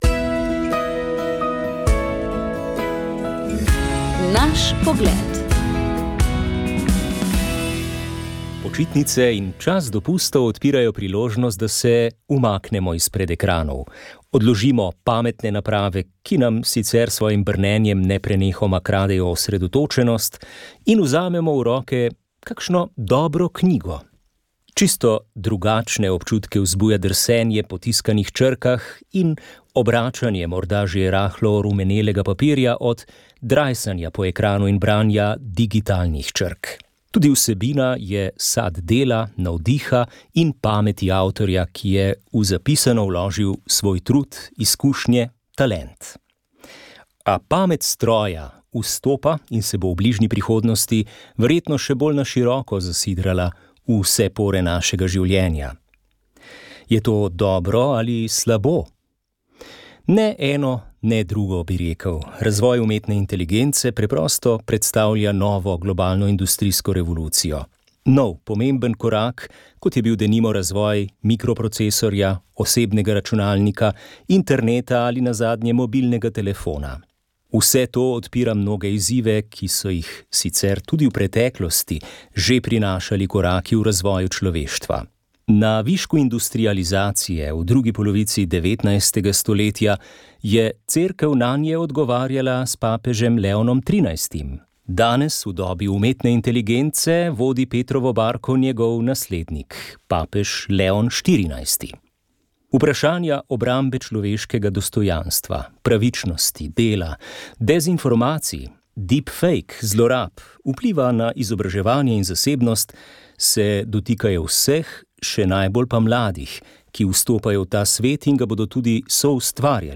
V zgodovinsko obarvani oddaji smo se pogovarjali s člani komisije za muzejsko dejavnost, ki deluje pri Zvezi društev slepih in slabovidnih Slovenije. Njihov namen je zbrati, ohraniti in dati širši javnosti na vpogled zapuščino, ki priča o razvoju možnosti za izobraževanje, zaposlovanje, delo in drugo udejstvovanje slepih in slabovidnih. V ta namen so pripravili muzejsko razstavo z naslovom Od točkopisa do Ebralca in zbirko pričevanj, ki jim lahko prisluhnemo na spletu.